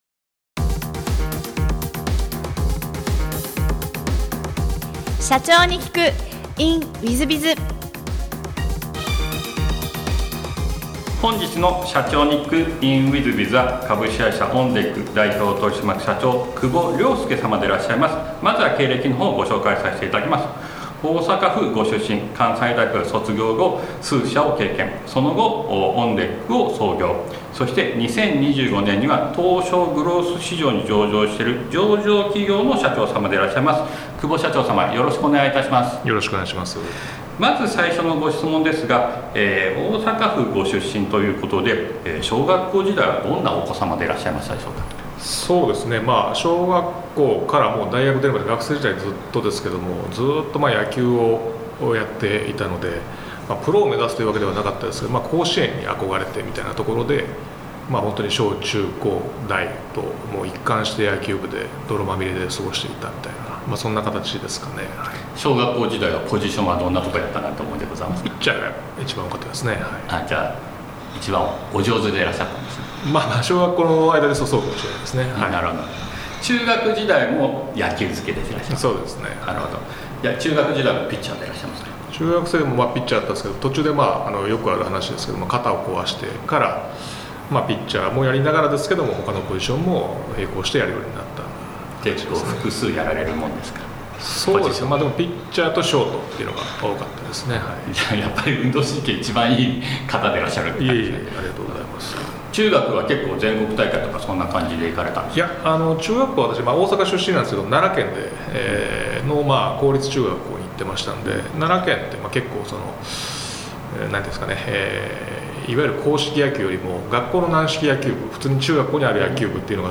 M&A事業で業績を上げ、上場へと導いたエピソードから経営のヒントが得られます。ぜひ、インタビューをお聞きください。